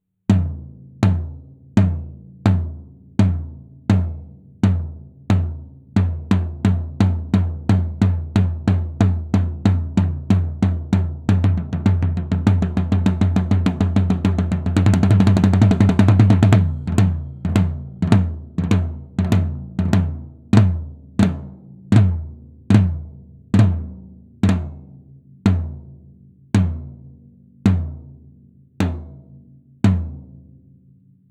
So haben wir ein Sennheiser E 604 als Mikro an das Zoom R 16 gehängt und auf ein Wahan CustomPro Acryl 14x12 geschlagen.
Aufgehangen wurde es an einem RIMS. Selbstverständlich wurden die Dateien nicht manipuliert, ihr hört folglich das, was das R 16 aufgenommen hat.
Tom1
Ich bilde mir aber ein, dass der Sustain beim Gehängten etwas länger ist, die Füße trotz Ringes beim Bodenständigen den Sunstain kürzen.
Ganz klar hat Tom 1 ein etwas längeres Sustain!